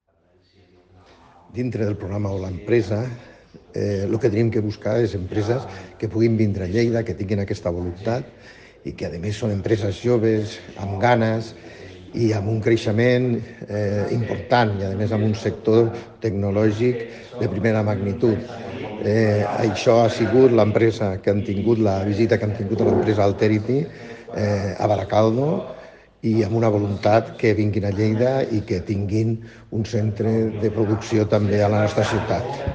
Tall de veu P. Cerdà Alterity, amb seu principal a la localitat bilbaïna de Baracaldo, es va concebre a iniciativa de 5 estudiants de la Universitat Politècnica de Catalunya.